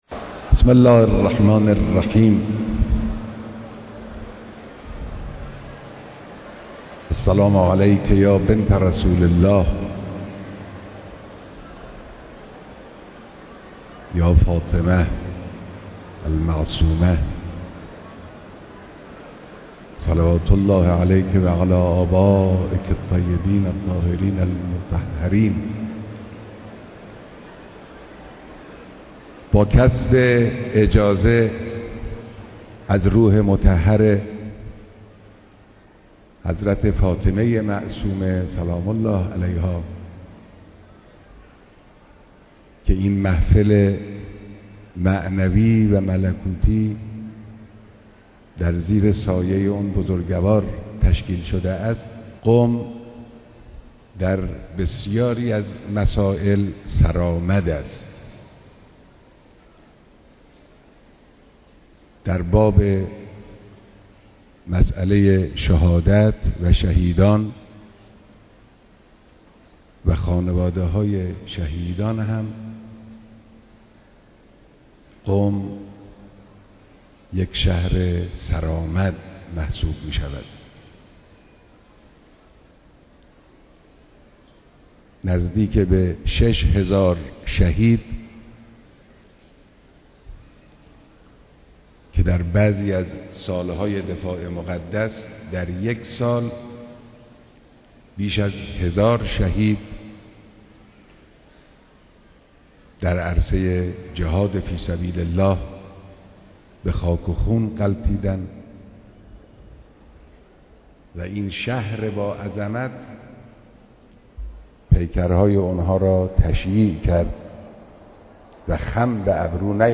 بيانات در ديدار خانواده‌هاى شهدا، جانبازان قم